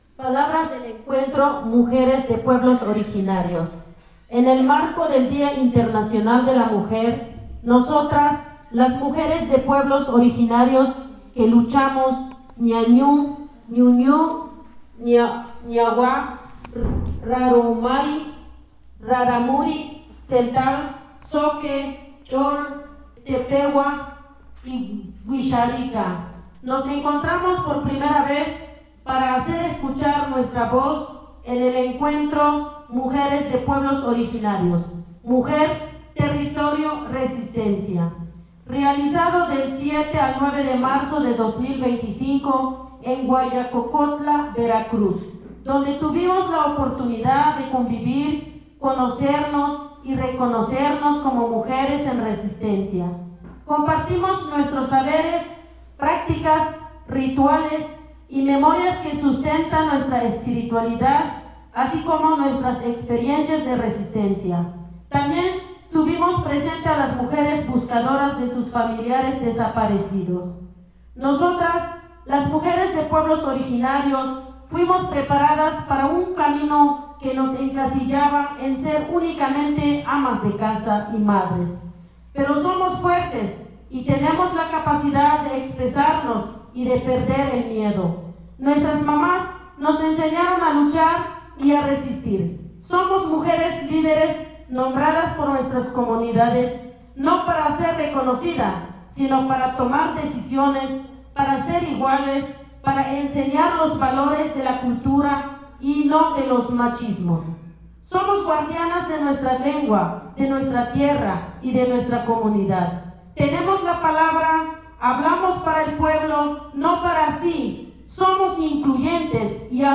Se realiza el encuentro de Mujeres de pueblos originarios. Radio Huaya fue la sede
Escucha el pronunciamiento de las mujeres.
DECLARATORIA-MUJERES.mp3